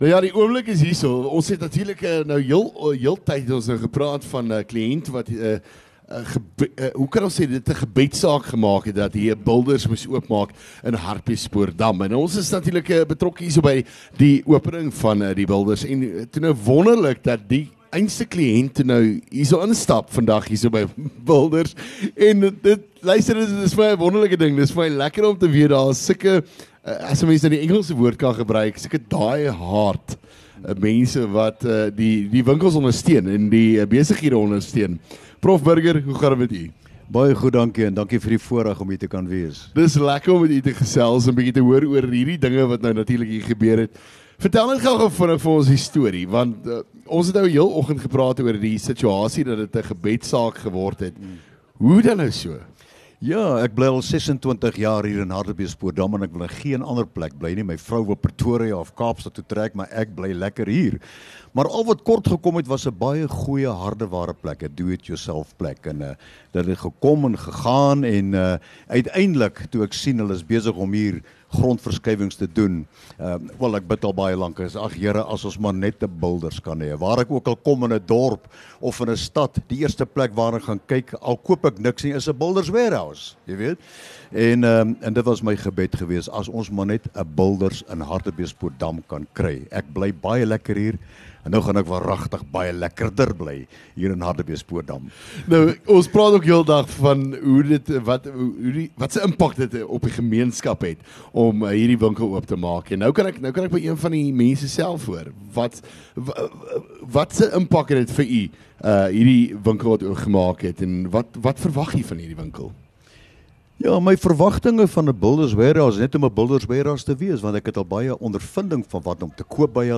LEKKER FM | Onderhoude 28 Nov Builders Wharehouse